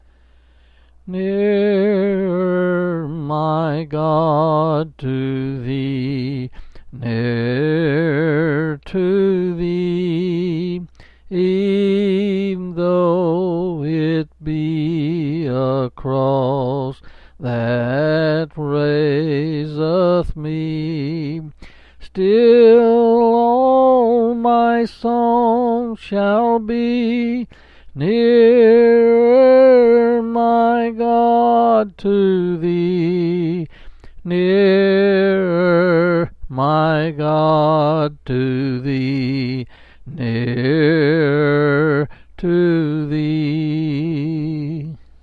Quill Pin Selected Hymn
Bethany. 8s and 4s